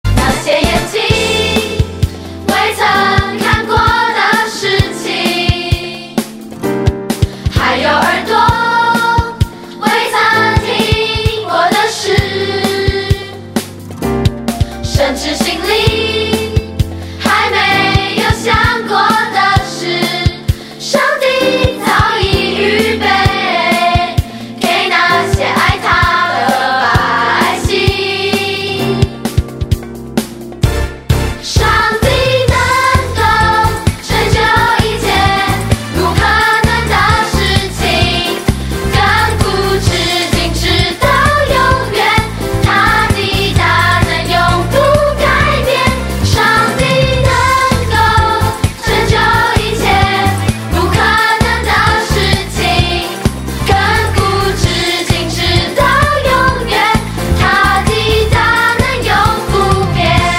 全部商品 / 音樂專區 / 中文專輯 / 兒童敬拜
12首充滿活力與感動的敬拜讚美+ 7首傳遞堅定愛神的精彩MV
聽見孩子真摯的歌聲 可以改變您的心情  看見神所創造的美好